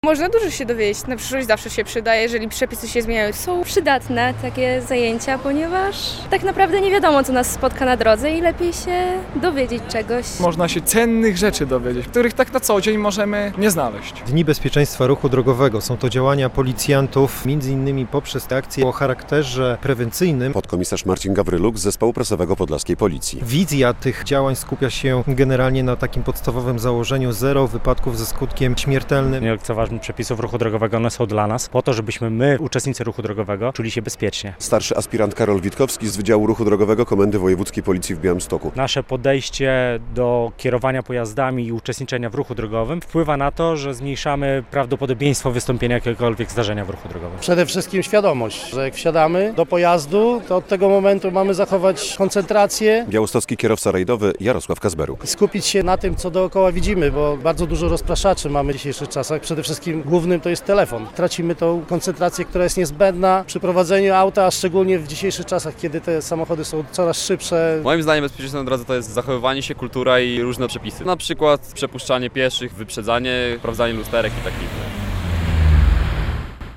Uczniowie białostockich szkół mundurowych wzięli udział w zajęciach w ramach akcji ROADPOL Safety Days - relacja